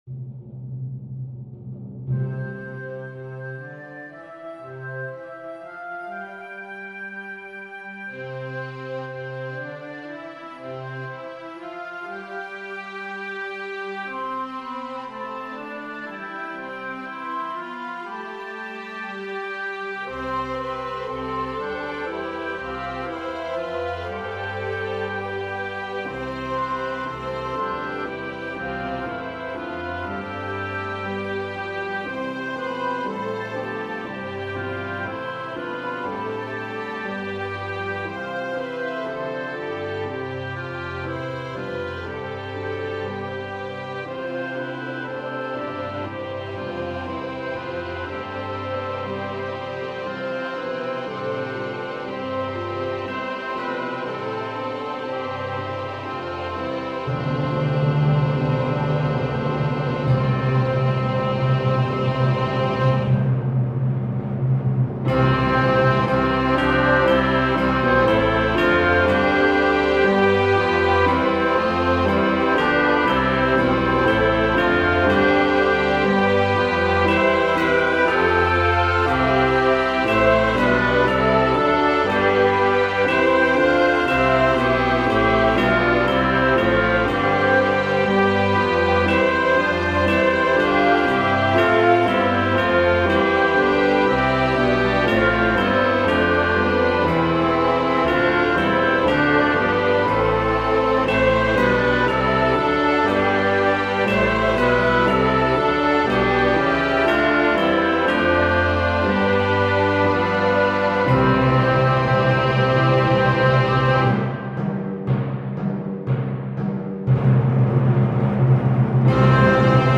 It starts with the first verse performed as a round, with each group of instruments/choir joining in turn and ending on a unified chord/note.
Each subsequent verse adds complexity and power, concluding with the last majestic verse and postlude.